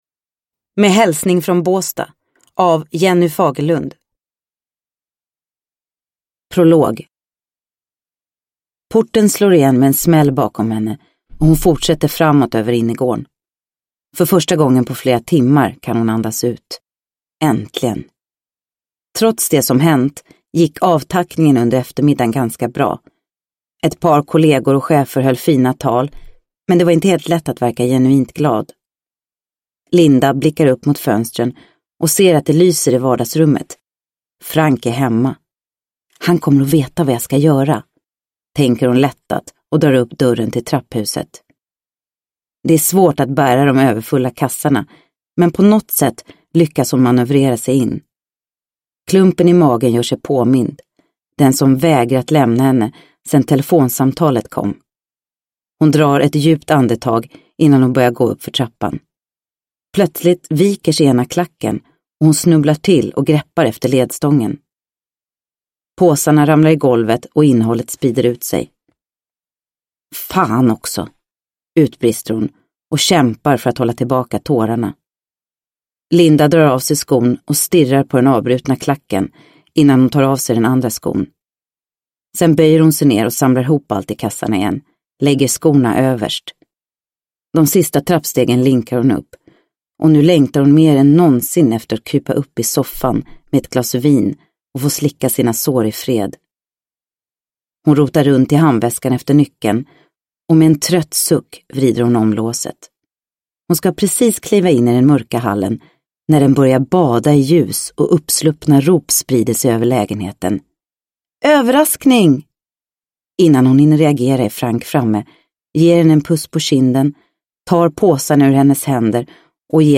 Med hälsning från Båstad – Ljudbok – Laddas ner